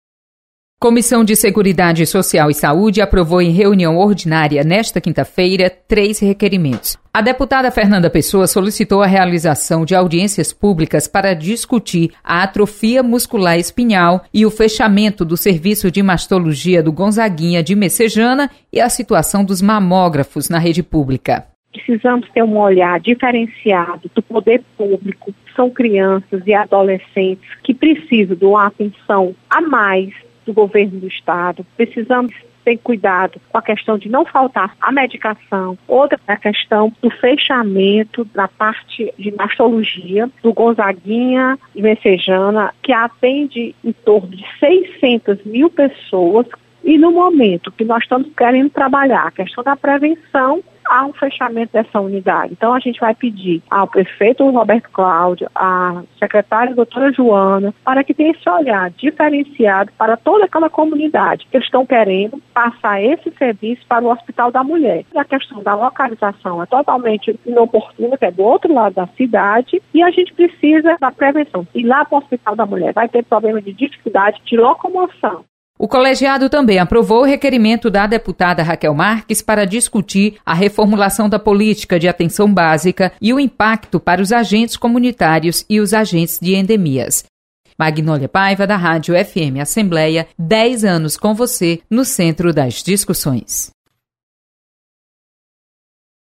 Acompanhe o resumo das comissões técnicas permanentes com a repórter